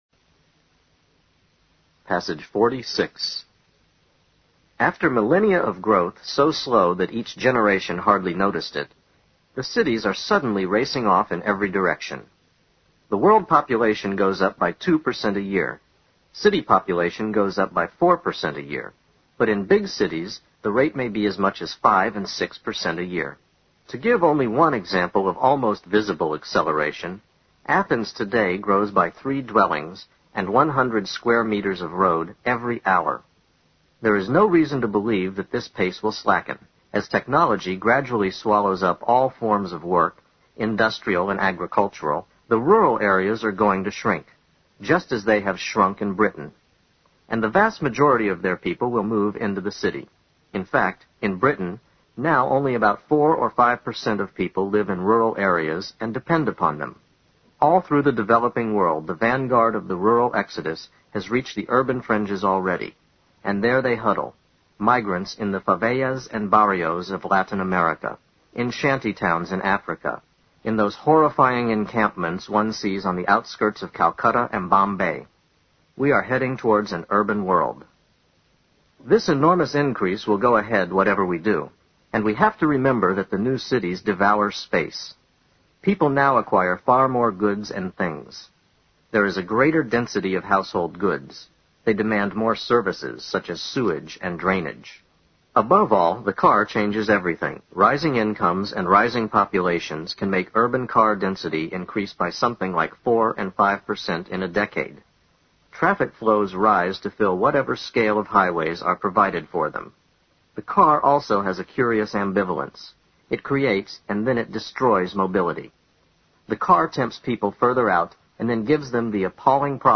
新概念英语85年上外美音版第四册 第46课 听力文件下载—在线英语听力室